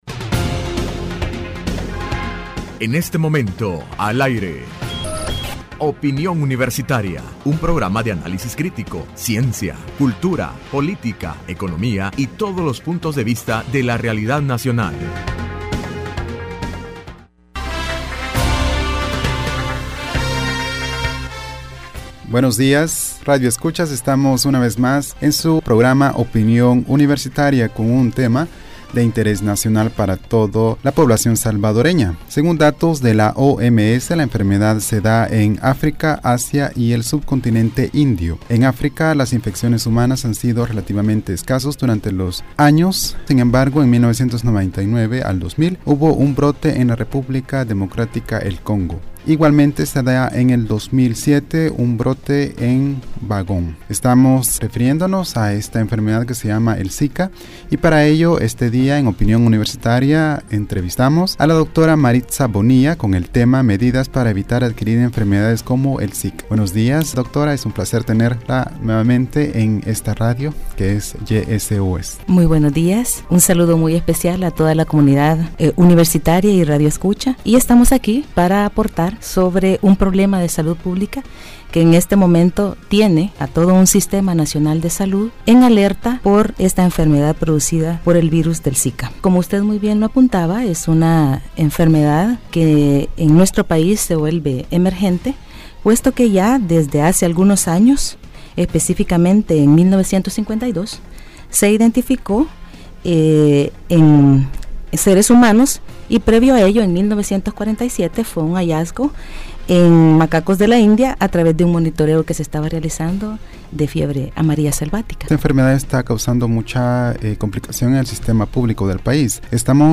Entrevista Opinión Universitaria (11 de Febrero de 2016): Medidas para evitar enfermedades como El Chikungunya, Zika, Dengue.